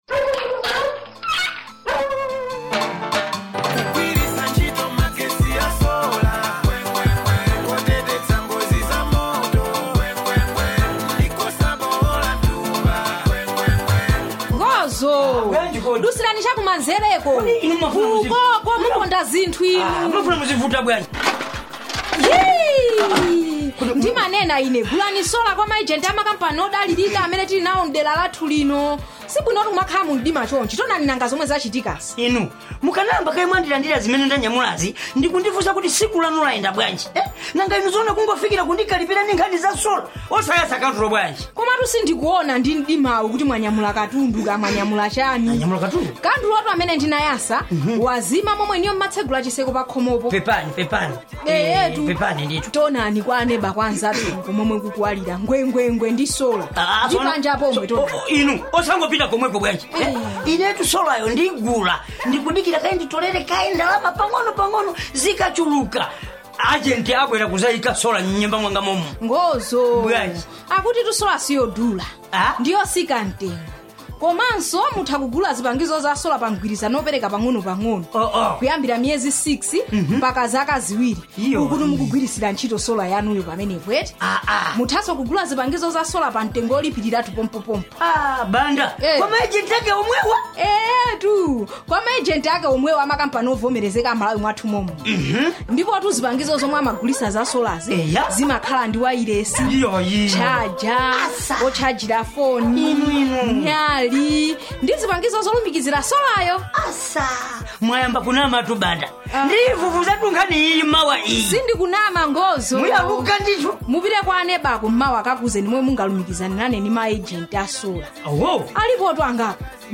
NNNF Chichewa Radio Skit 1
CHICHEWA RADIO SKITS 1.mp3